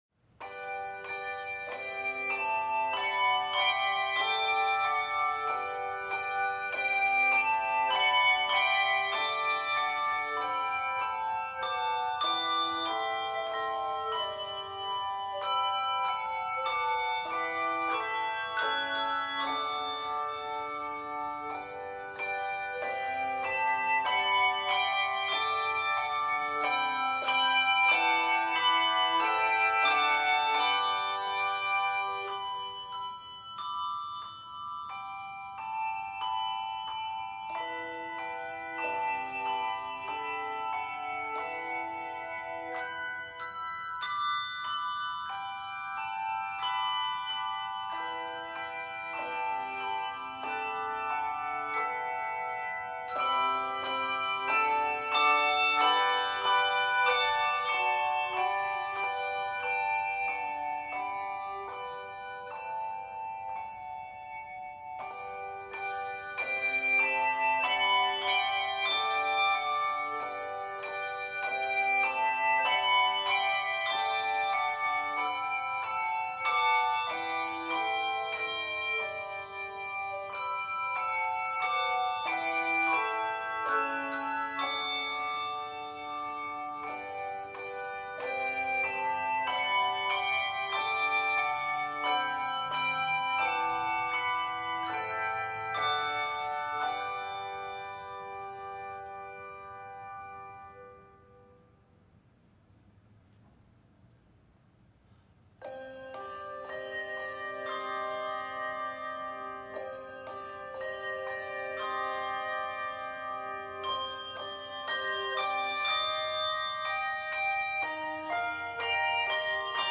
Two Christmas carols from the United States
2 or 3 octaves of handbells